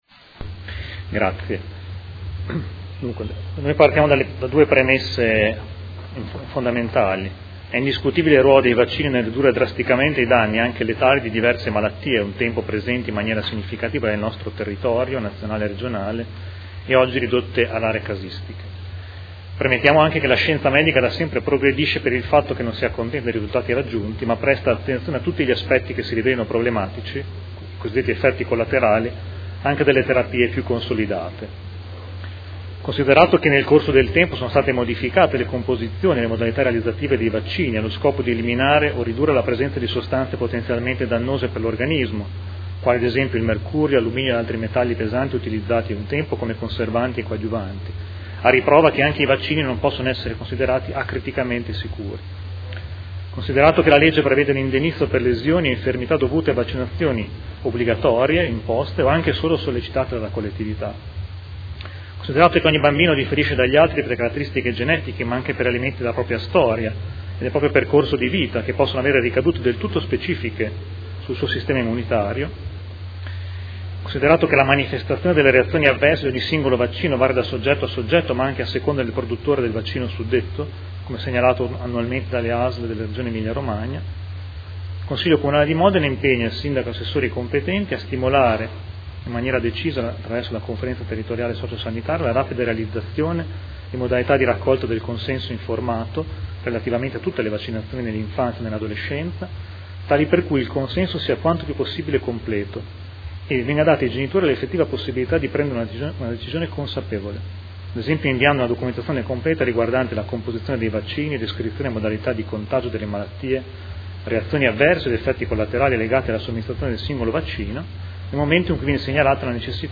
Mario Bussetti — Sito Audio Consiglio Comunale
Seduta del 14/01/2016. Ordine del Giorno presentato dai Consiglieri Busseti, Rabboni, Bortolotti e Scardozzi (Movimento5Stelle) avente per oggetto: Vaccinazioni pediatriche